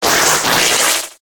Cri de Morpeko Mode Affamé dans Pokémon HOME.
Cri_0877_Affamé_HOME.ogg